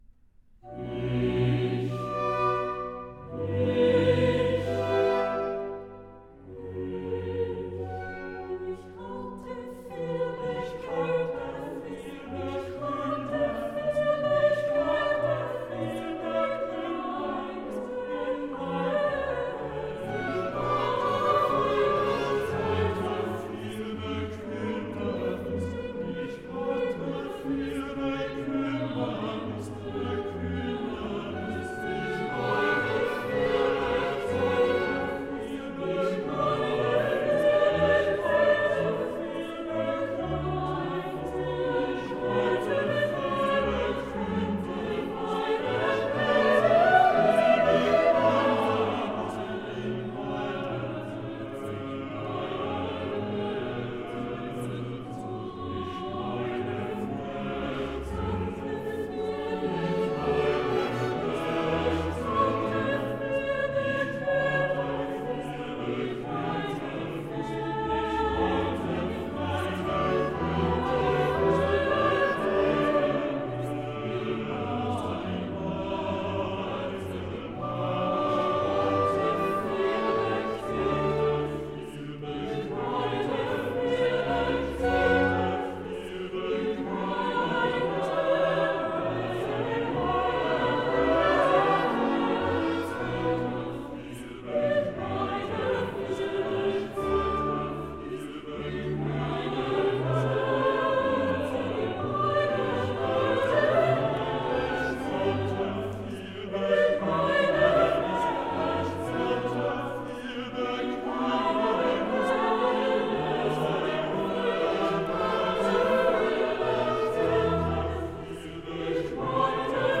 Ich hatte viel Bekümmernis (Chorus)